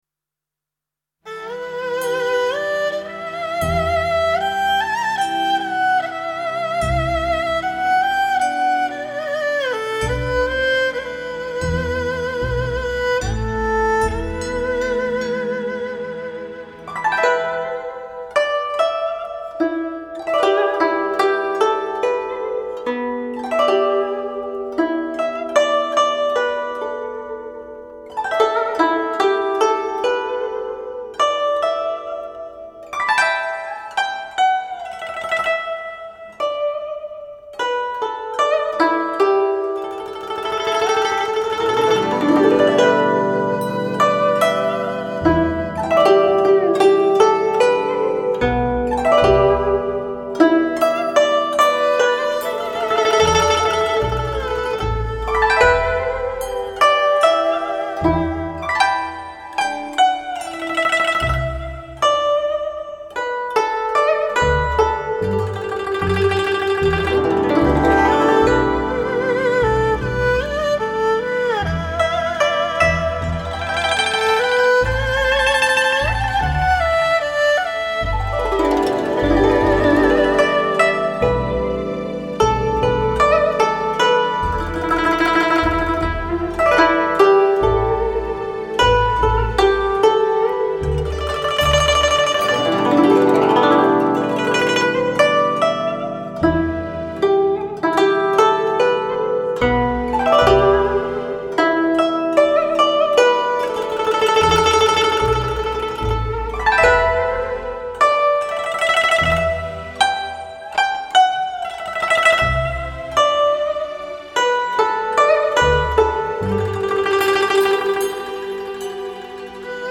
（情为何物/古筝） 激动社区，陪你一起慢慢变老！